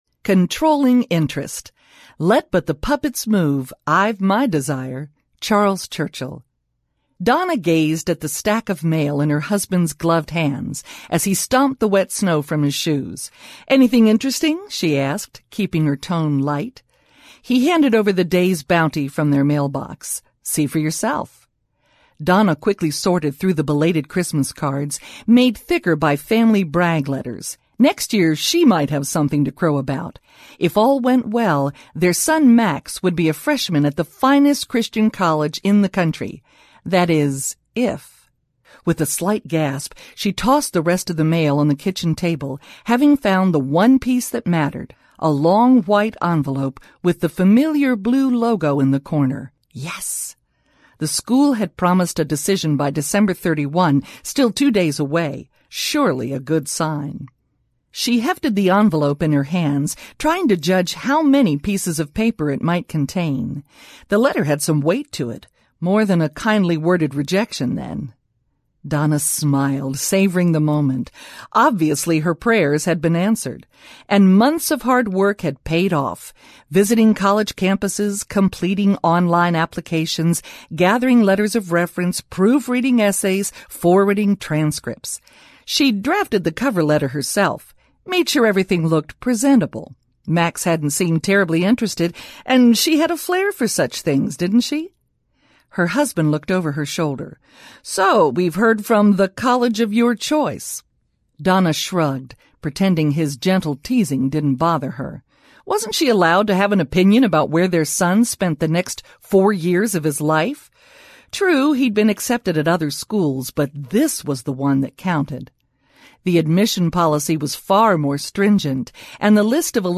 Slightly Bad Girls of the Bible Audiobook